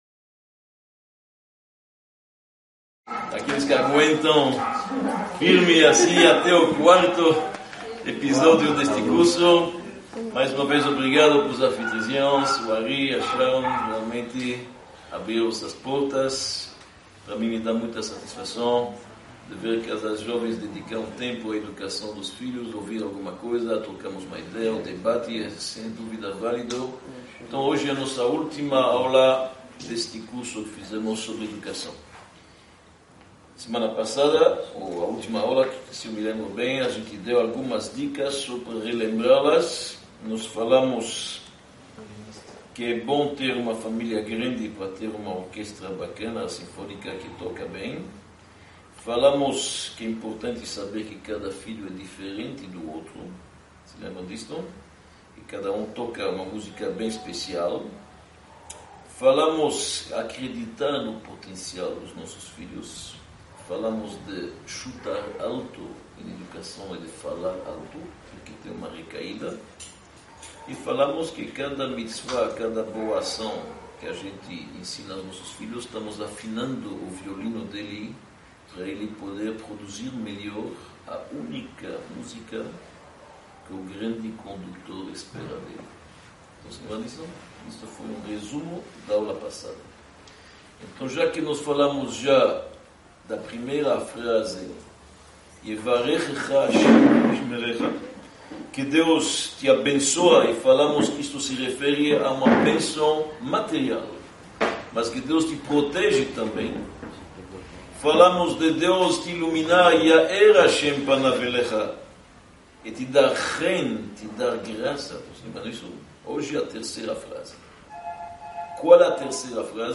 Palestra-Parte-4_-Na-educação-mesmo-errando-corrigimos-e-recomeçamos-1-1.mp3